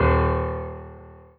piano-ff-07.wav